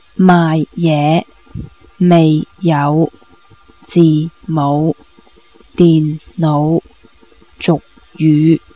Tone Drills
(Vocab in each column shares the same tones pattern)
LF = low falling (tone 4),  LR = low rising (tone 5),   LL= low level (tone 6)
Table 6 - From tone 6 LL (low level) to other tones: